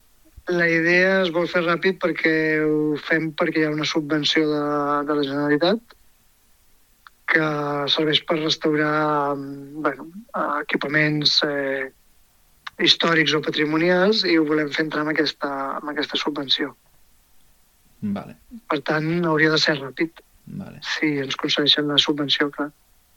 Actualment s’ha acabat de redactar el projecte de restauració de la torre de la Masia Bas, que inclourà la instal·lació d’una passarel·la des del primer pis de l’edifici fins a la torre, facilitant així l’accés a persones amb problemes de mobilitat. Són declaracions de Josep Amat.